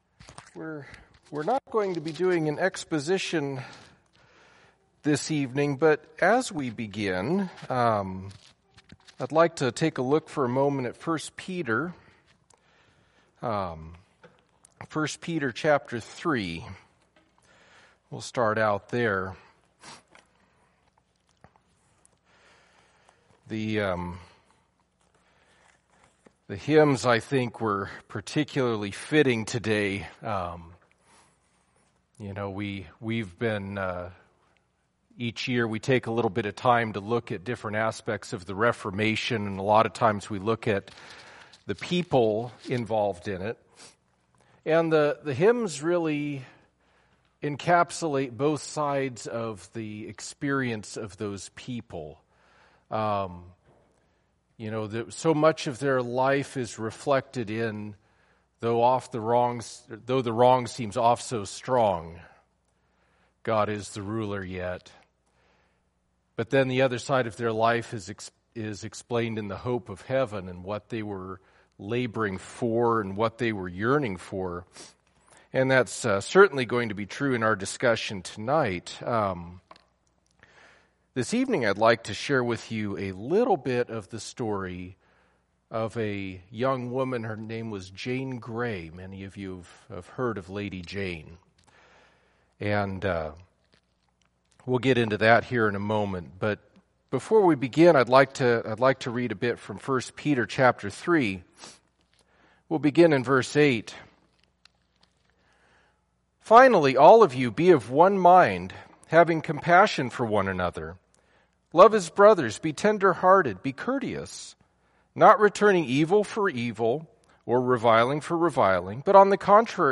1 Peter 3:8-18 Service Type: Wednesday Evening Topics